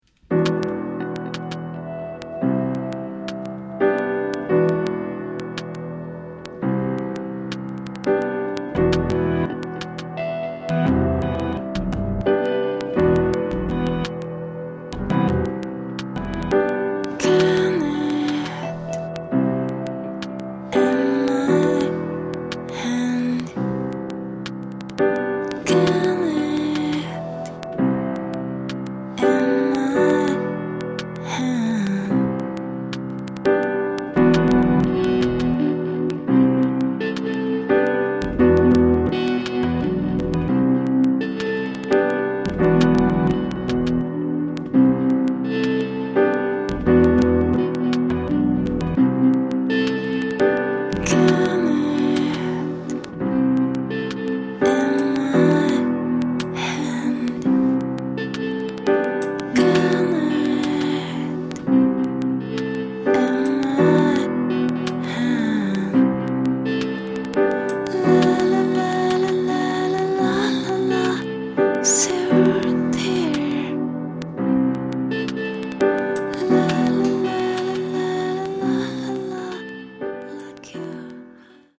しっくいの壁。すりガラスの窓。使い込まれた家具。そして静かな町の空気。